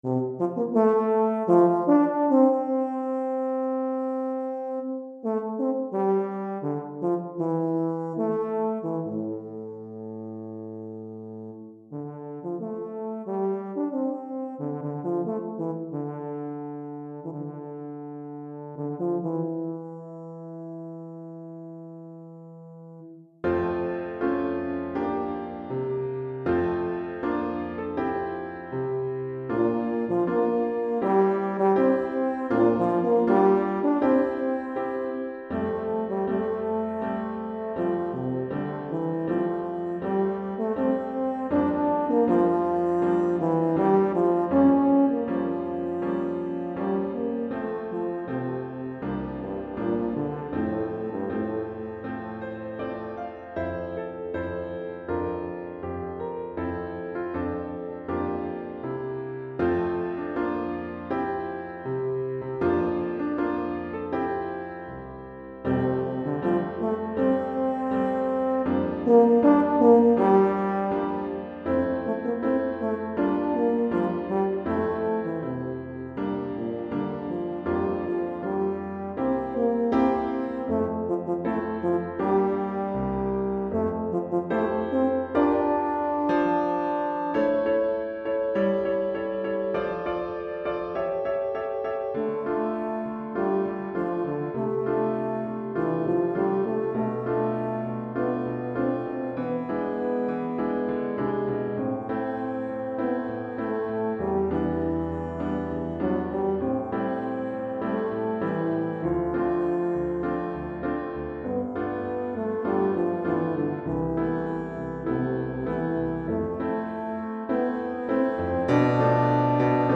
Pour euphonium ou saxhorn et piano